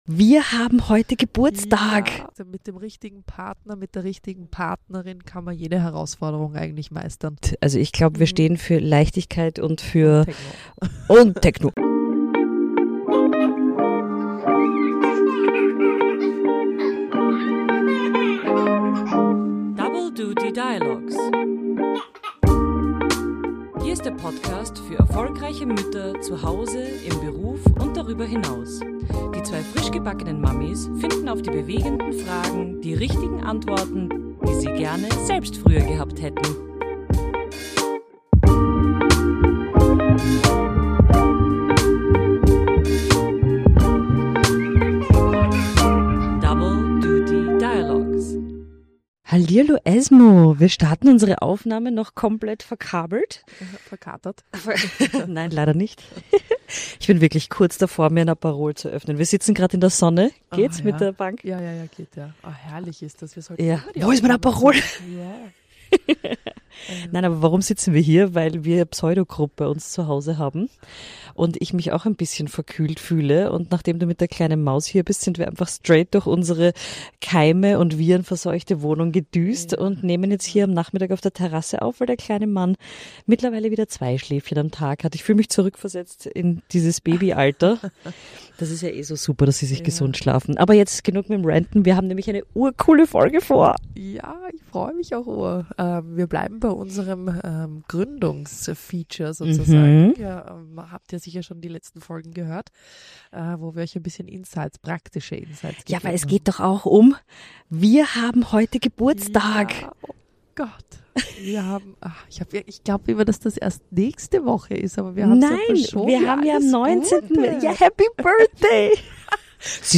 Vor einem Jahr haben wir mit Double Duty Dialogues gestartet – zwei frischgebackene Mamis, Mikros und viele Fragen rund um Karriere, Familie und das eigene Ich dazwischen. In dieser Jubiläumsfolge blicken wir zurück auf ein Jahr voller ehrlicher Gespräche, großer und kleiner Ahas und einer Community...